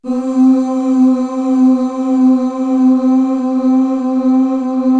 Index of /90_sSampleCDs/Best Service ProSamples vol.55 - Retro Sampler [AKAI] 1CD/Partition C/CHOIR UHH